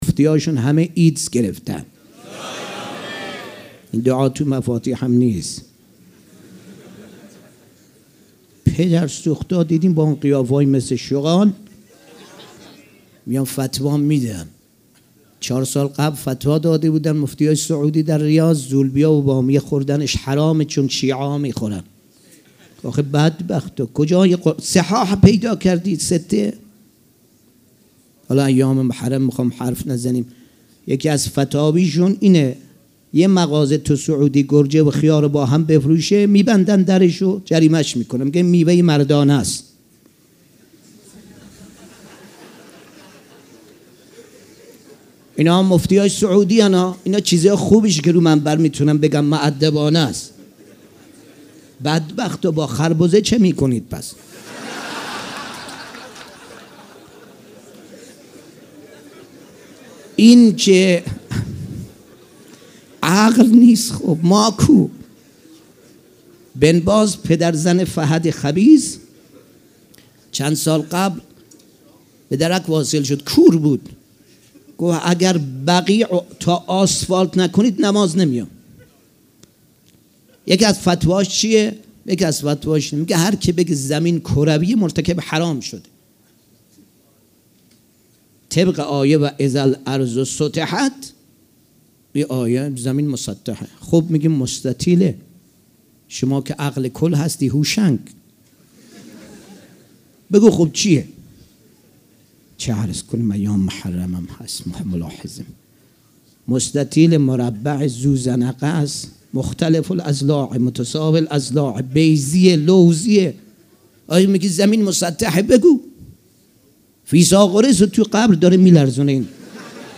سخنرانی
شب دوم محرم الحرام‌ دوشنبه ۱۲ مهرماه ۱۳۹۵ هيئت ريحانة الحسين(س)